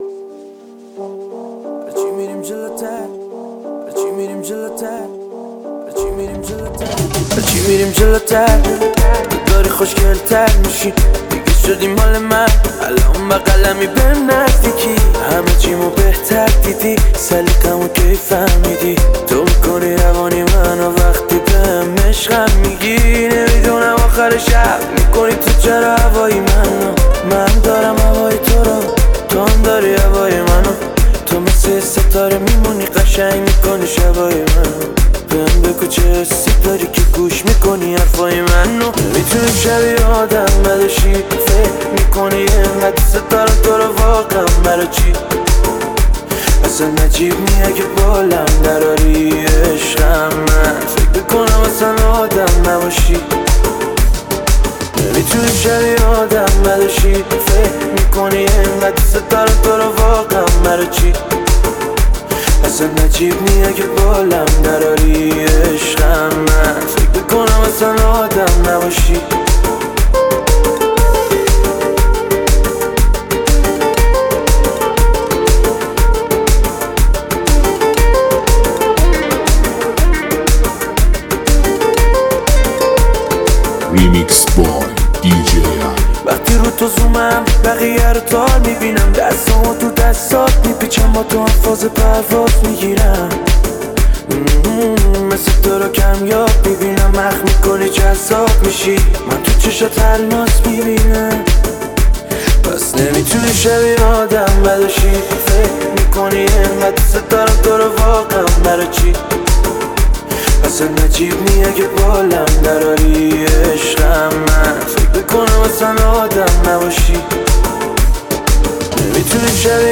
ریمیکس تند بیس دار
ریمیکس دوم